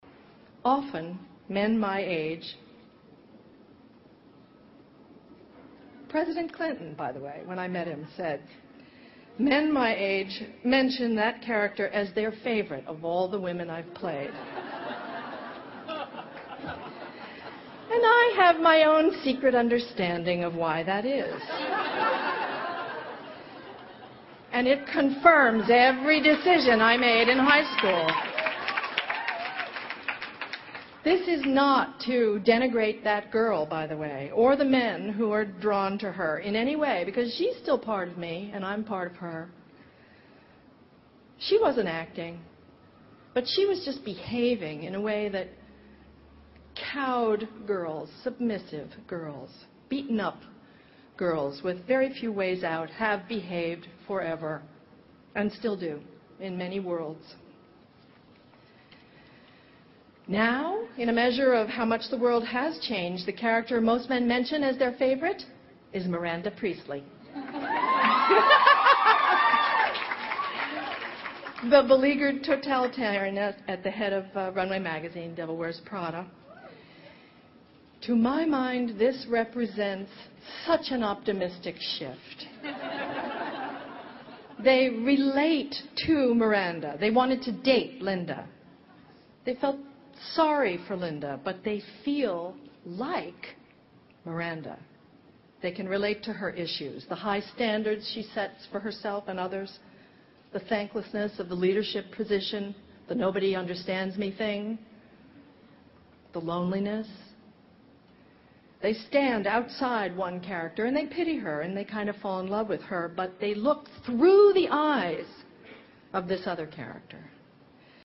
偶像励志英语演讲 第50期:让父母为你骄傲(10) 听力文件下载—在线英语听力室